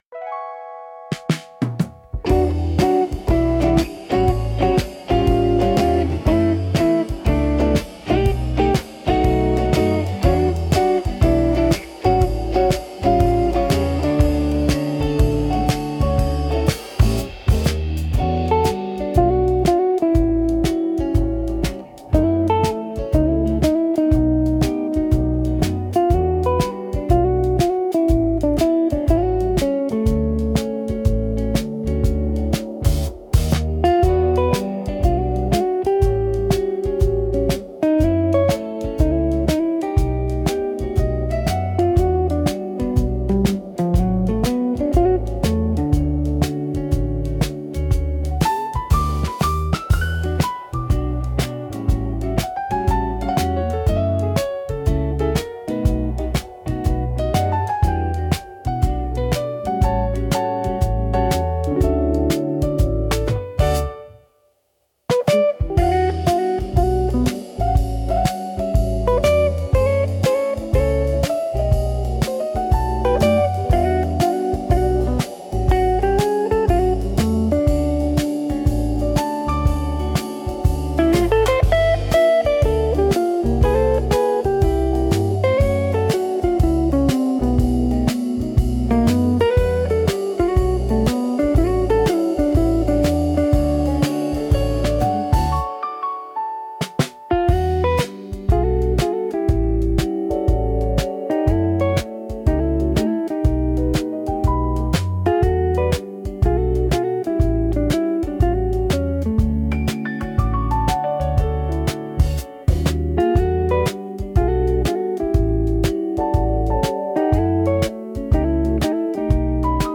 素朴なギターの爪弾きが安心感を与え、心地よいリズムがゆったりとした時間の流れを演出します。
• ジャンル： アコースティック / フォーク / ノスタルジック
• 雰囲気： レトロ / 温かい / 郷愁 / 素朴 / 優しい
• テンポ（BPM）： ミドル（心地よい歩みの速さ）